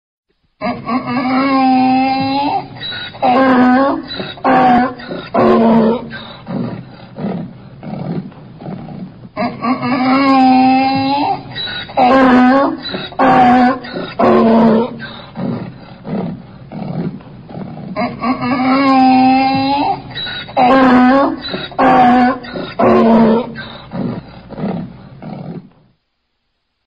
Ringetone Æsel
Kategori Dyr